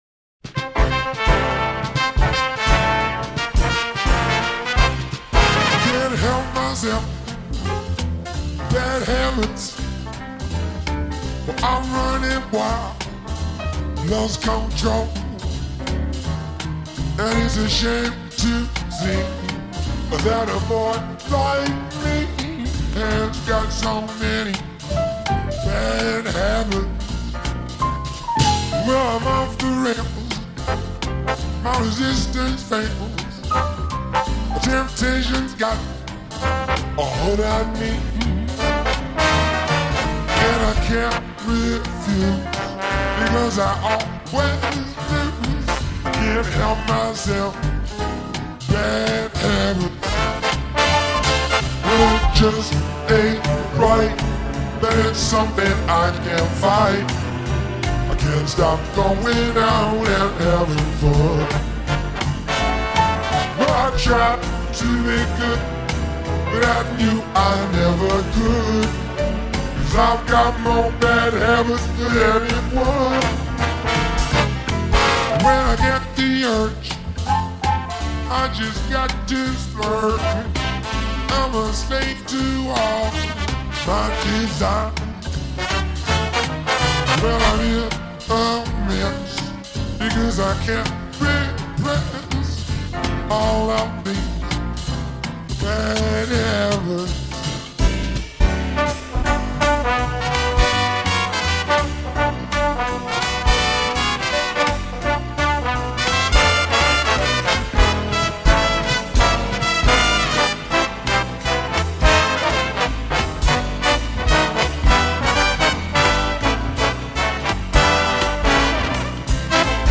and audio in F